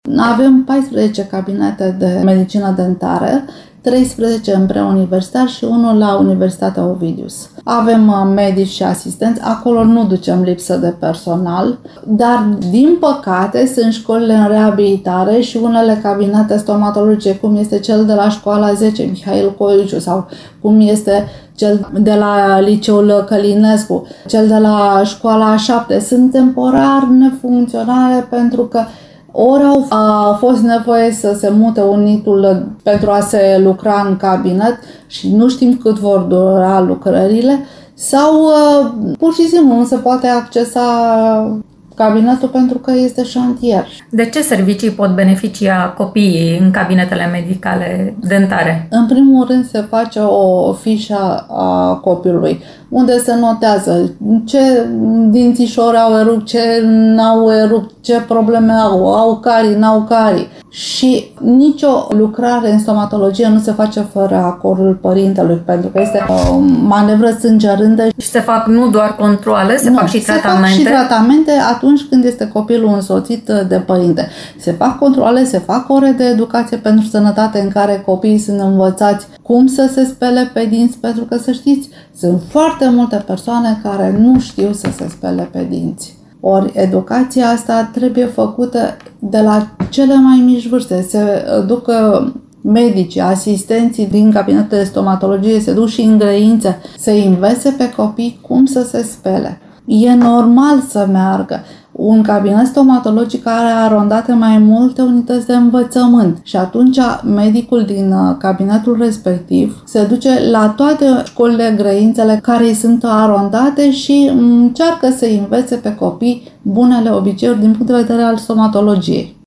Care este situația din școlile constănțene, dar și de ce servicii stomatologice pot beneficia elevii aflați din interviul